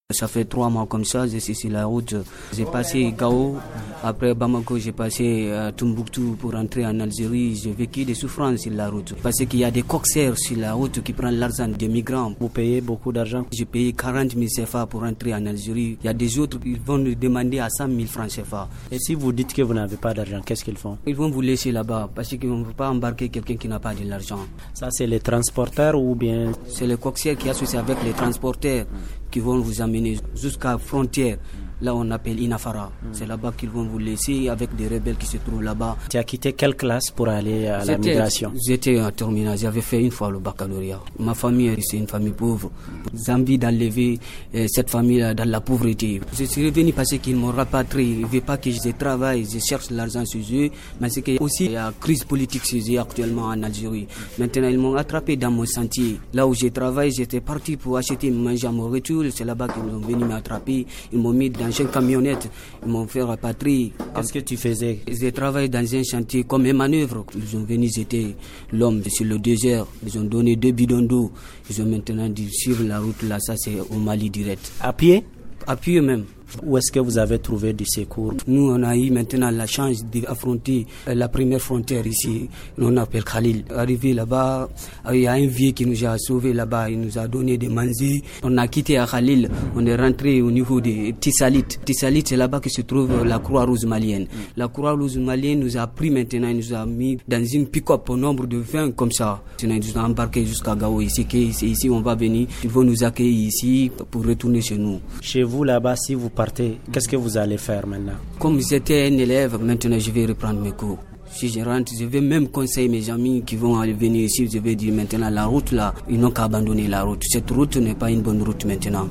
Notre magazine d’aujourd’hui porte sur le témoignage d’un jeune migrant guinéen refoulé fin octobre 2019, de l’Algérie vers Gao au Mali. Il était avec 25 autres compagnons d’infortune.